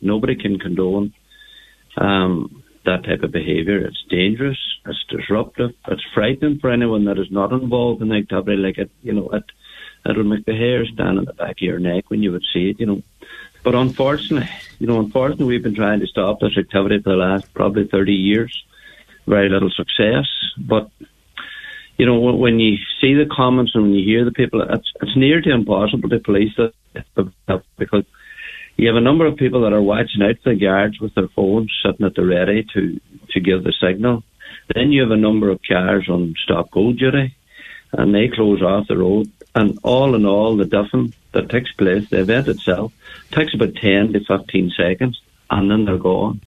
Meanwhile, local Cllr Paul Canning has condemned the act: